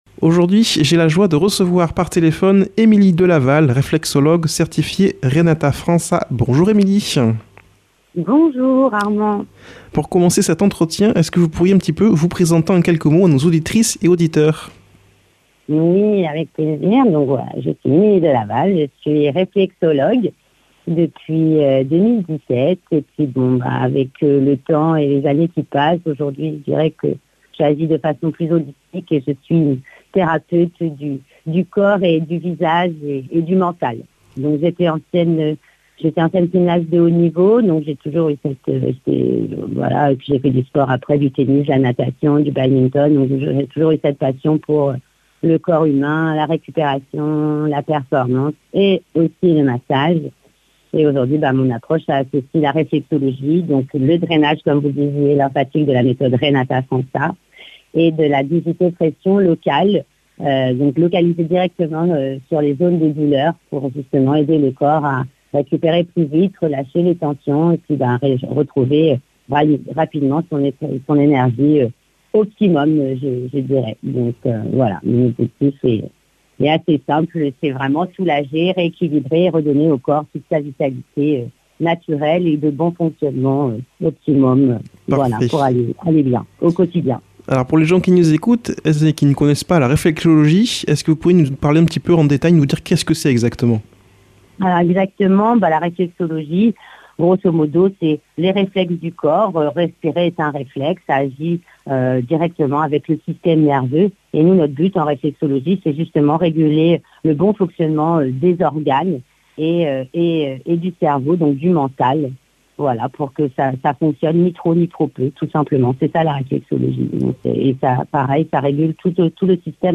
invitée par téléphone